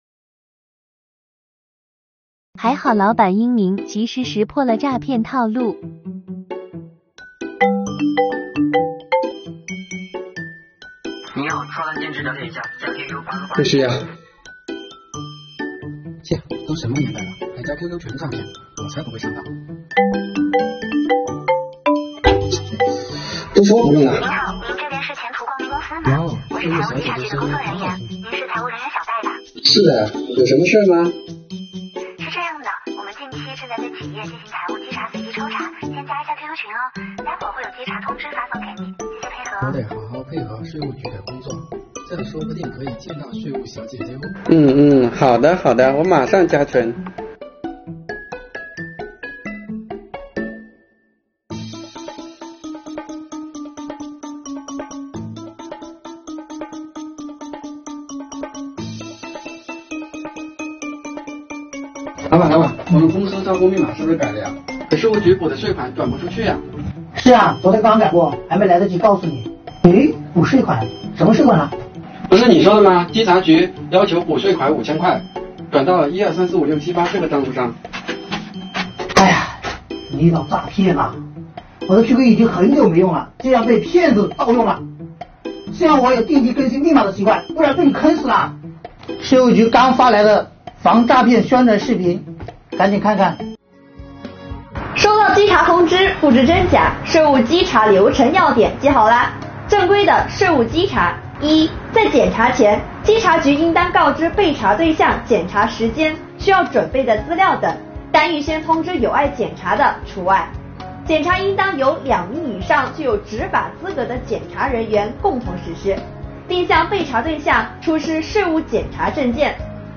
作品以情景小短剧的方式展开，背景音乐轻松活泼，与画面搭配得当。